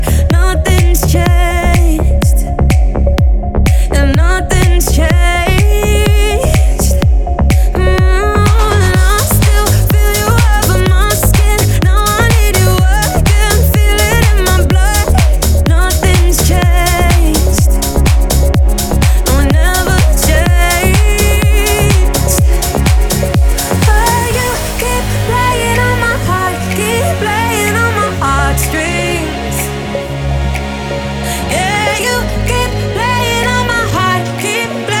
Жанр: Танцевальная музыка / Русские